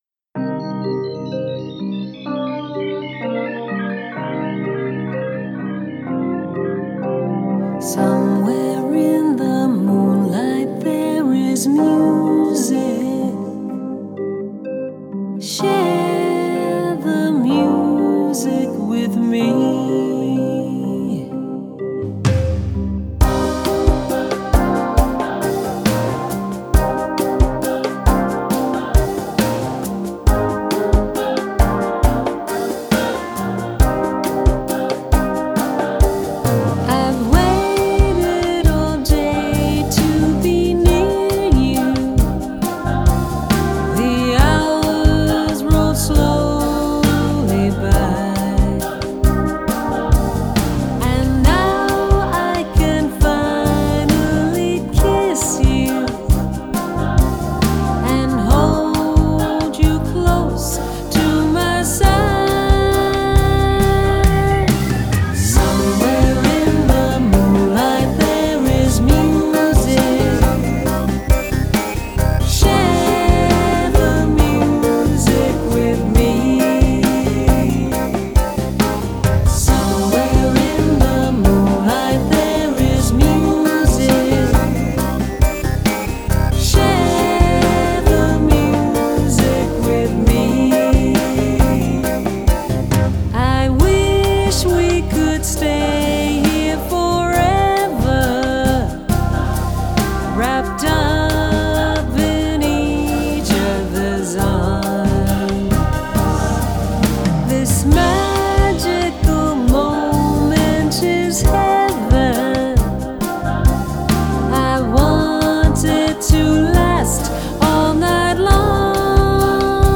Genre: Jazz/Pop Vocals